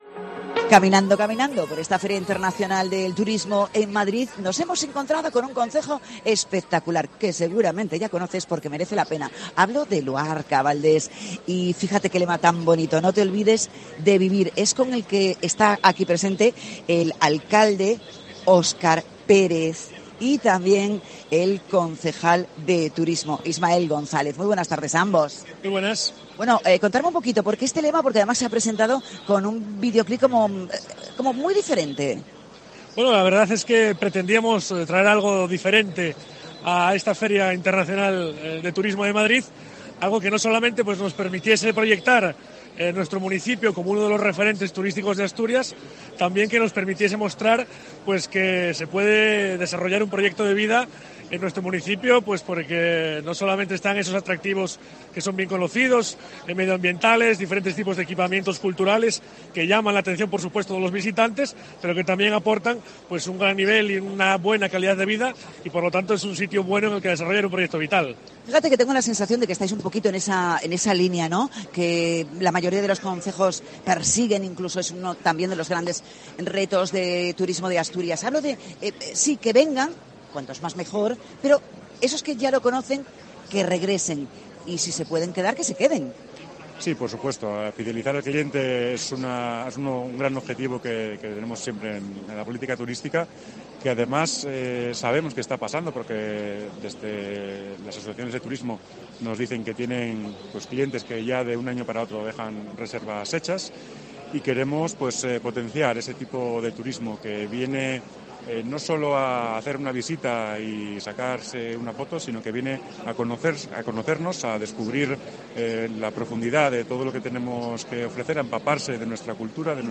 FITUR 2024: Entrevista a Óscar Pérez, alcalde; e Ismael González, concejal de Turismo de Valdés